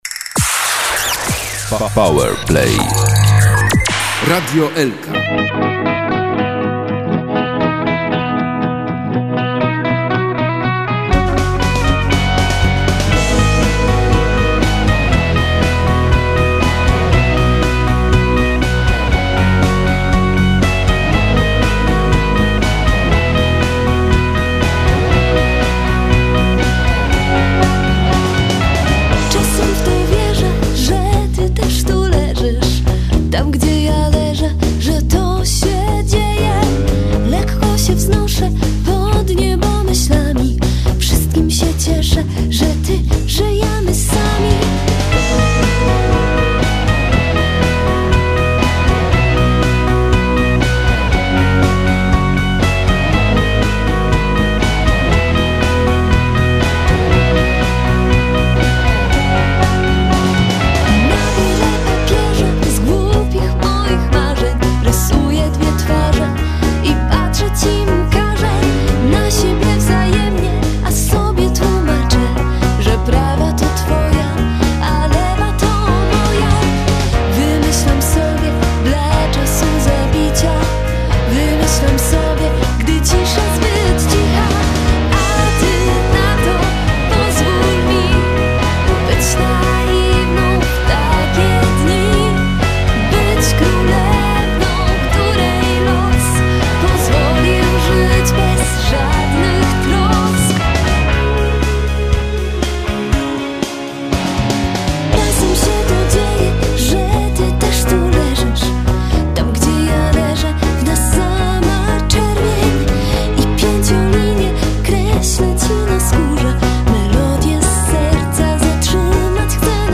prawdziwie wakacyjny singiel